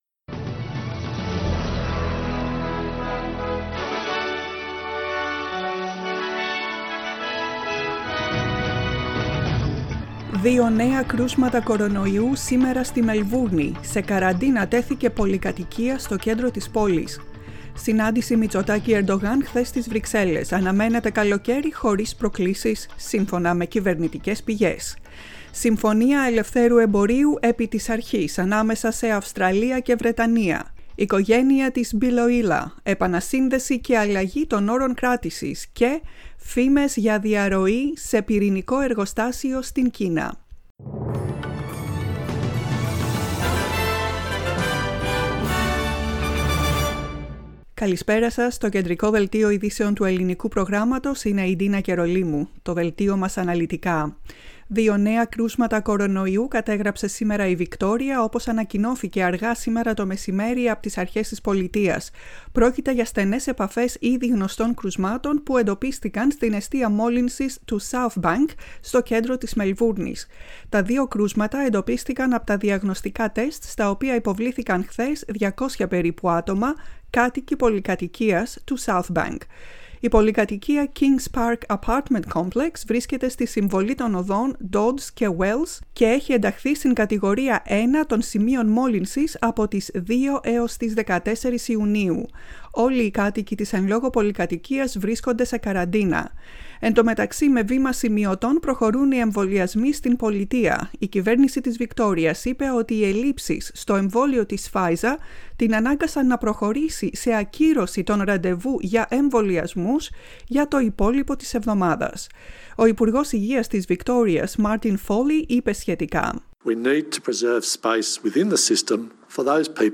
Δελτίο ειδήσεων στα ελληνικά, 15.06.21
Ακούστε το κεντρικό δελτίο ειδήσεων του Ελληνικού Προγράμματος.